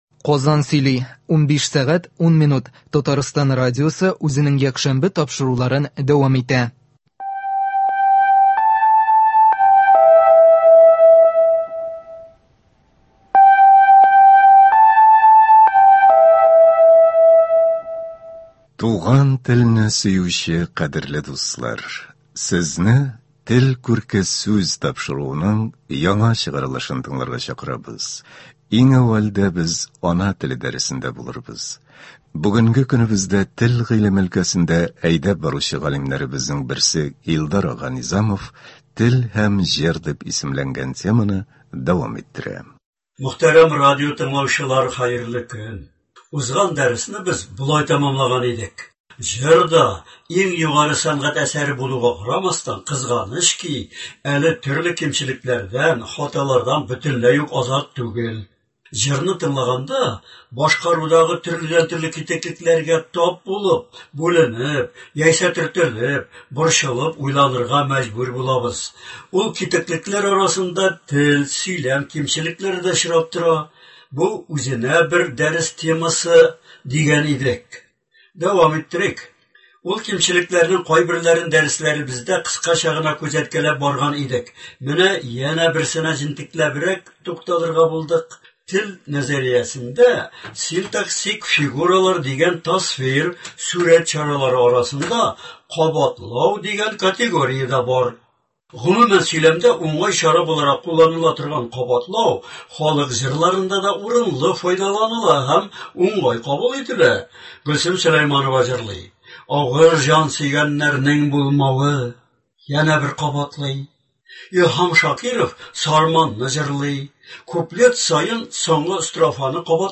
Бу тапшыруда күренекле галимнәр, язучылар халкыбызны дөрес сөйләшү, дөрес язу серләренә өйрәтә.